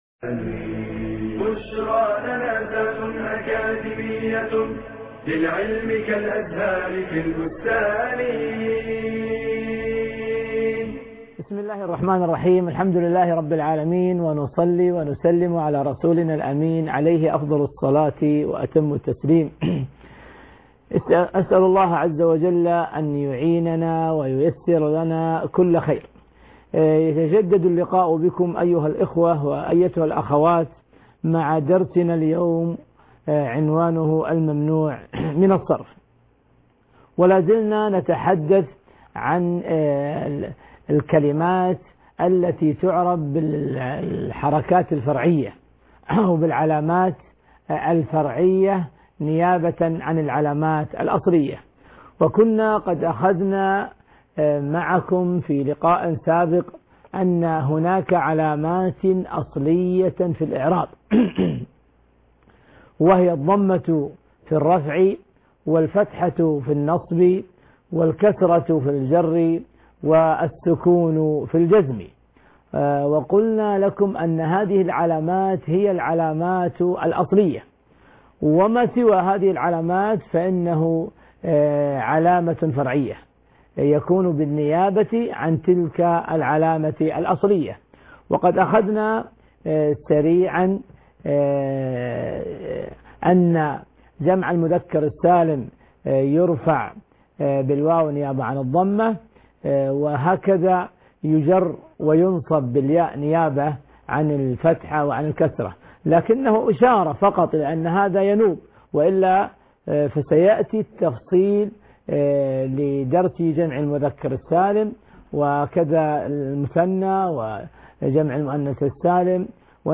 المحاضرة السابعة عشر